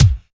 84 KICK 2.wav